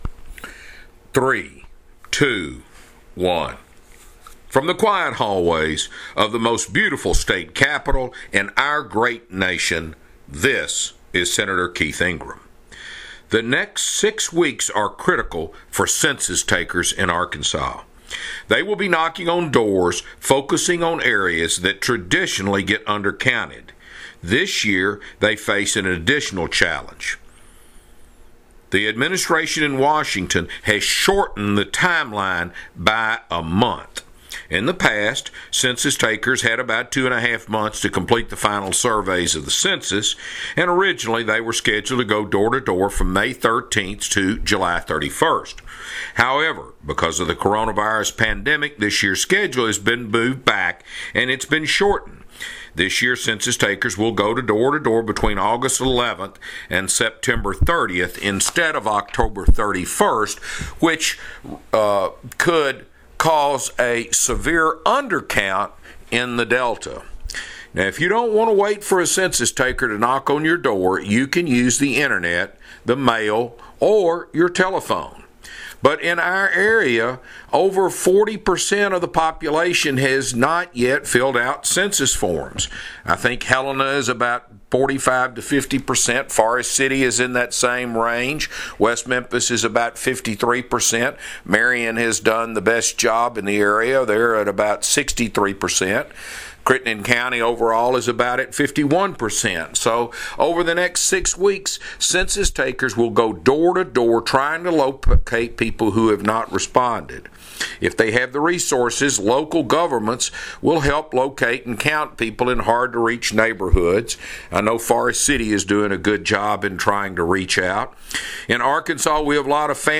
Weekly Address – August 14, 2020 | 2020-08-14T02:46:52.238Z | Sen. Keith Ingram